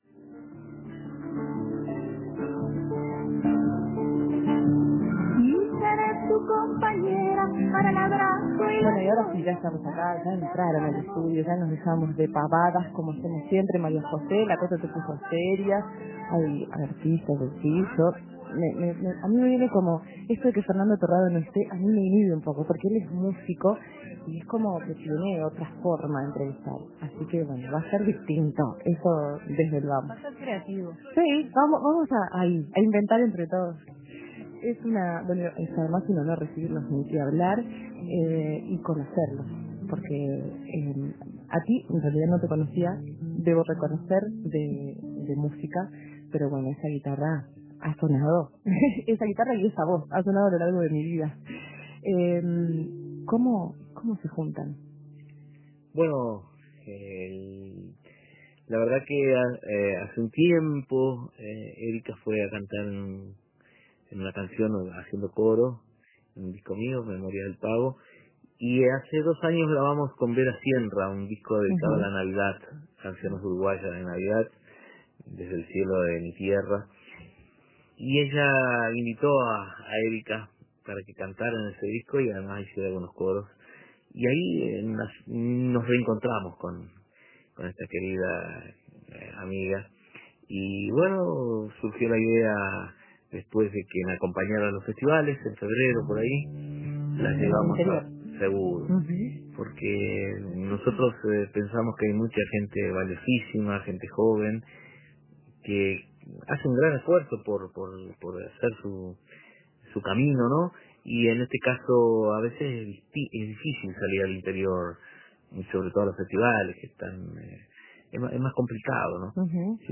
Voces y guitarras, ambas con cuerdas que concuerdan.